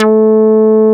P MOOG A4F.wav